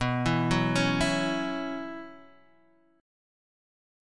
Bsus2sus4 chord